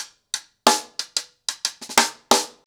Wireless-90BPM.19.wav